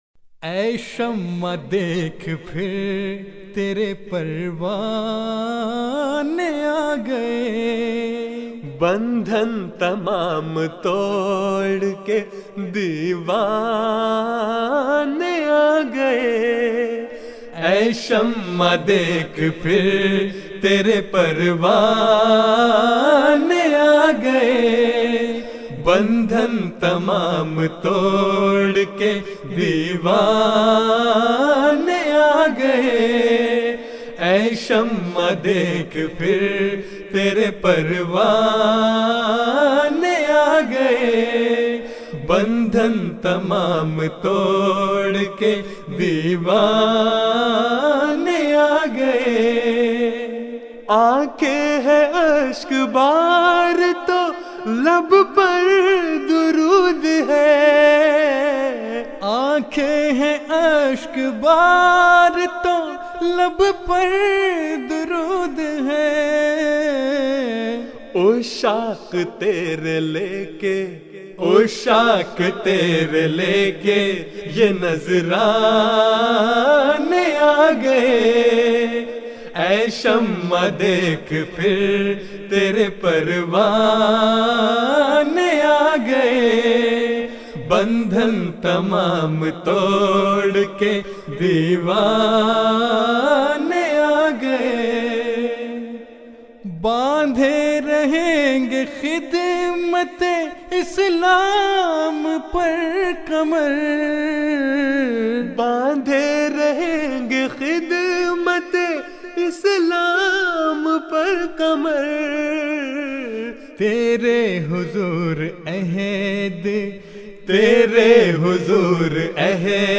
Voice: Group
Jalsa Salana UK 2010